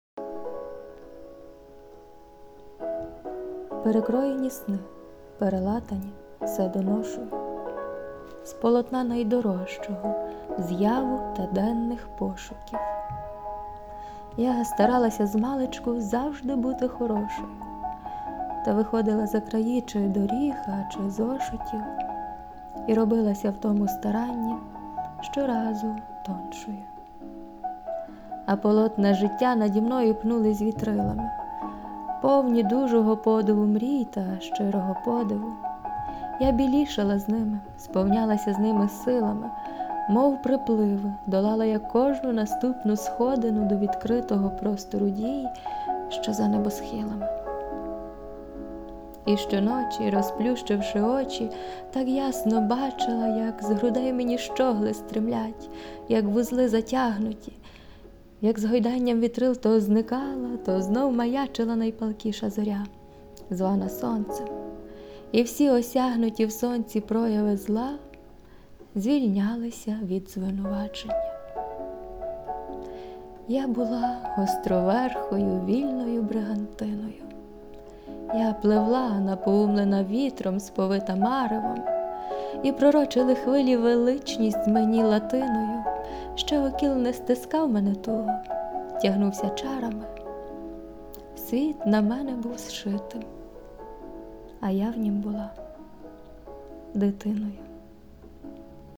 Фон – "Opstigning" Bremer/McCoy